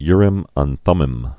(yrĭm ən thŭmĭm, rĭm; tmĭm)